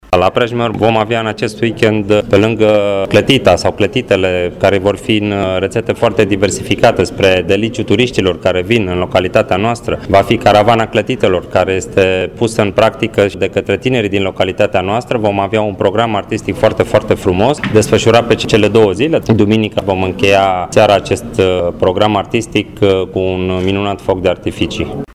Viceprimarul comunei Prejmer, Loredan Bogdan:
primar-loredan-bogdan.mp3